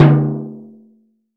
• Low Mid Tom Sound D Key 33.wav
Royality free tom sound tuned to the D note. Loudest frequency: 303Hz
low-mid-tom-sound-d-key-33-U07.wav